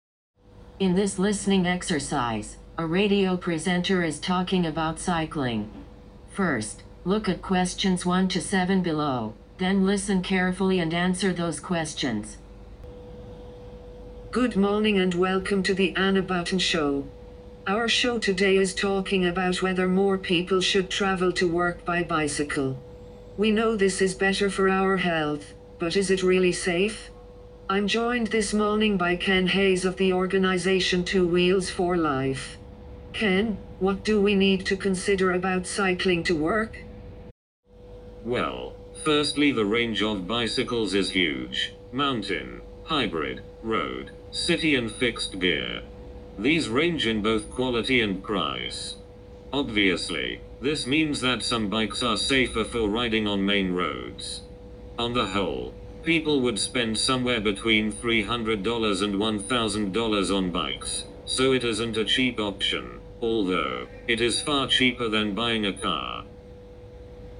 Listening Practice 10: Sentence Completion (North American, Irish & British) - EnglishVista | Free IELTS Test Prep | Learn English
Transcript In this listening exercise, a radio presenter is talking about cycling.